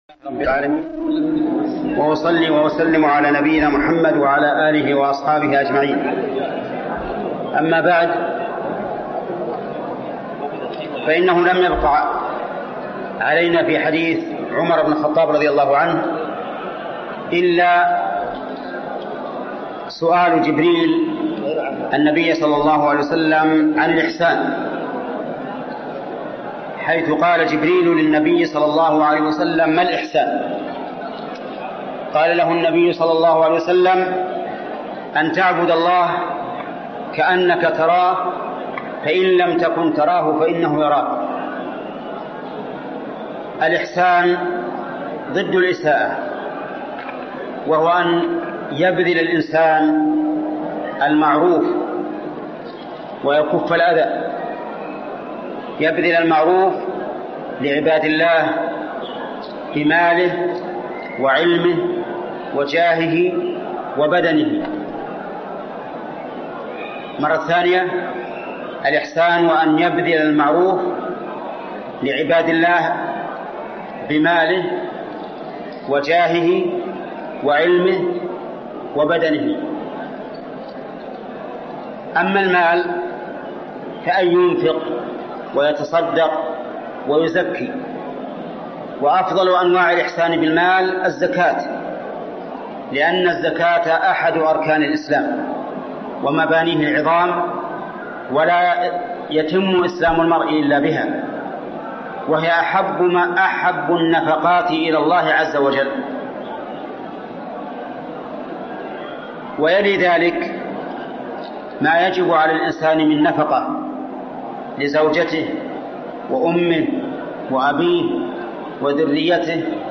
فتاوى ودروس في المسجد الحرام